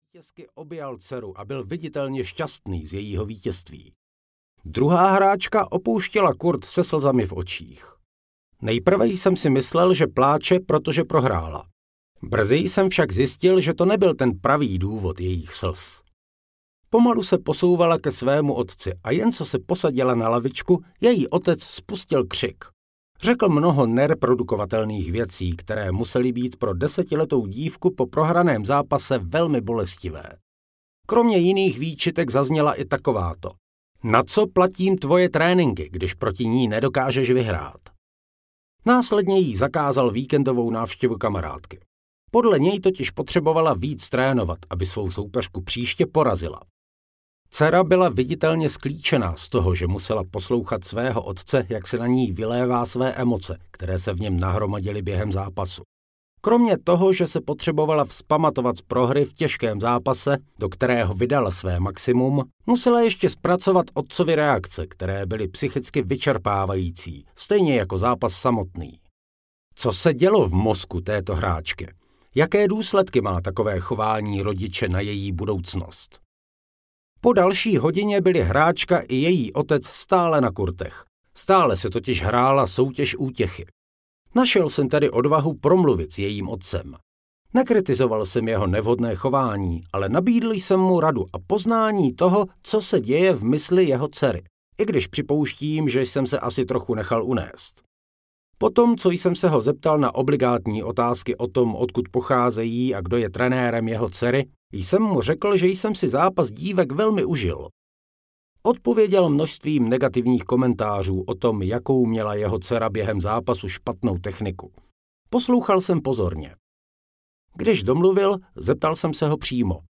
Bez strachu audiokniha
Ukázka z knihy